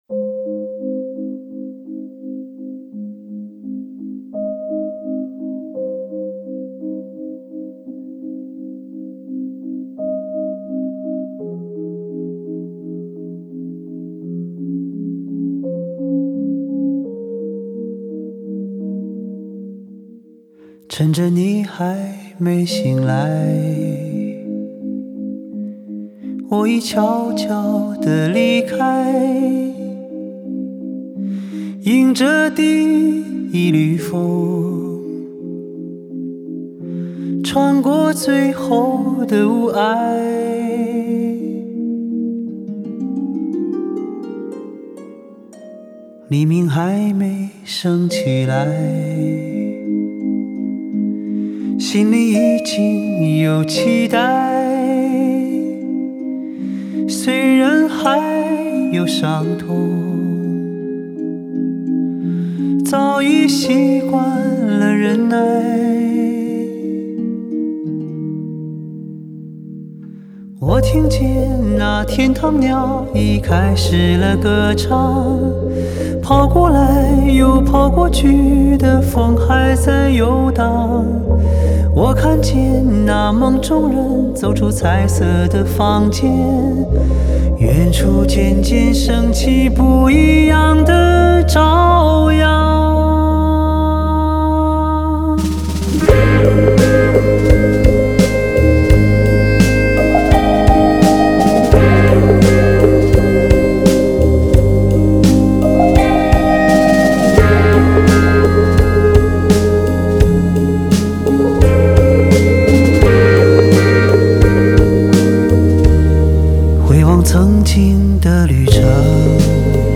精选26首魅力时尚流行歌曲
国内专挑精致情歌一流演绎